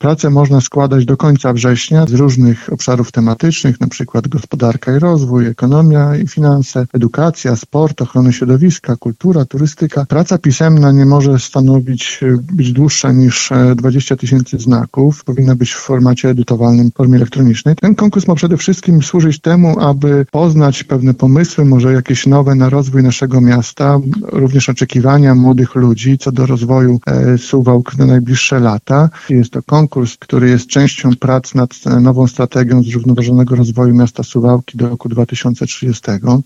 O szczegółach mówi Łukasz Kurzyna, zastępca prezydenta miasta.